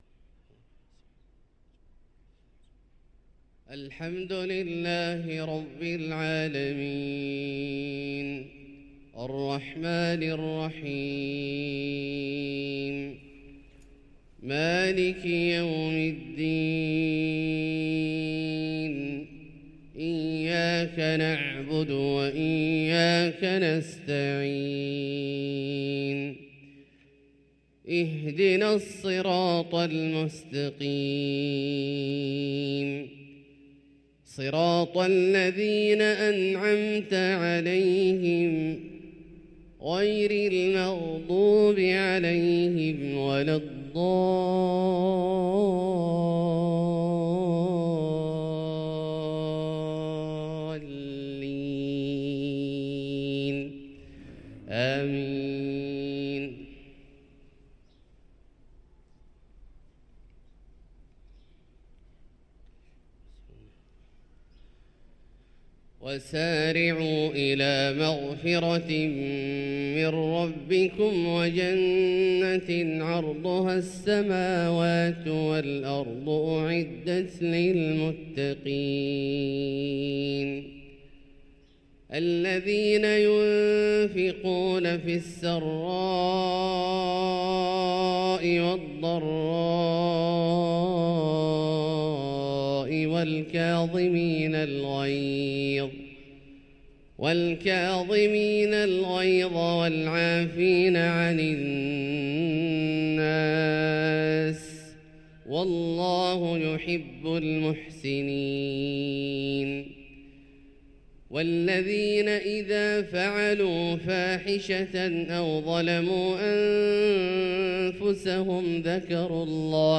صلاة الفجر للقارئ عبدالله الجهني 23 ربيع الأول 1445 هـ
تِلَاوَات الْحَرَمَيْن .